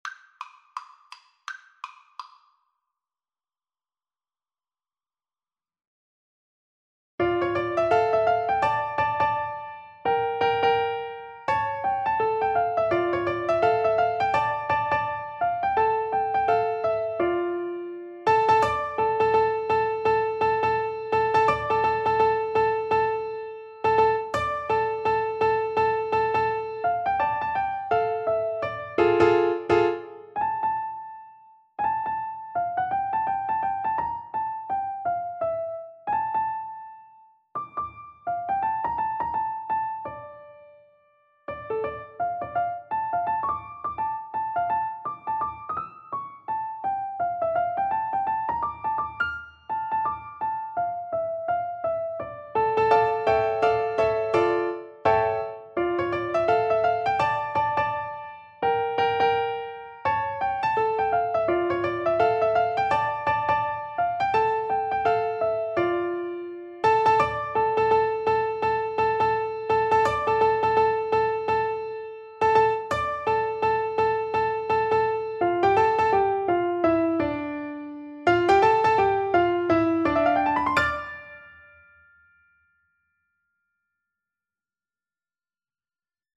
Quick Swing = 84
D minor (Sounding Pitch) (View more D minor Music for Piano Duet )